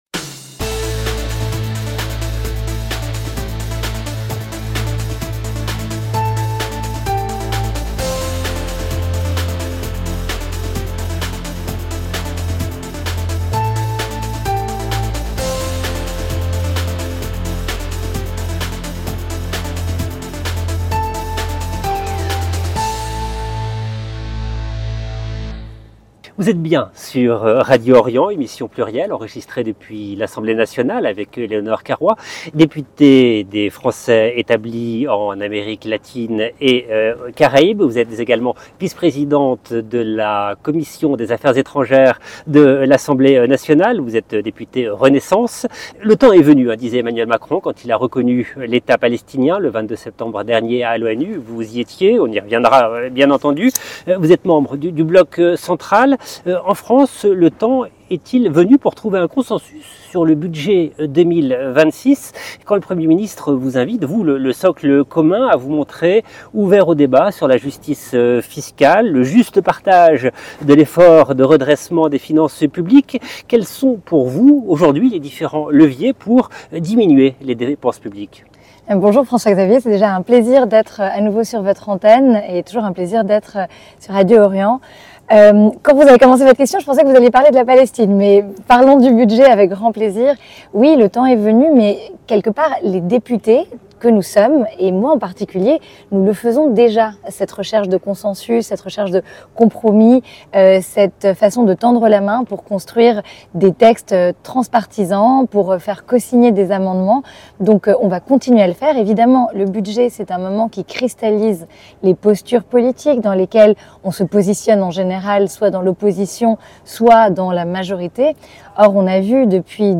Depuis l’Assemblée nationale, la députée Éléonore Caroit, vice-présidente de la Commission des Affaires étrangères, revient sur les grands sujets d’actualité : le projet de budget 2026, la reconnaissance de l’État palestinien par Emmanuel Macron, les relations France-Algérie, la condamnation de Nicolas Sarkozy, et les enjeux de prévention dans le cadre d’Octobre Rose.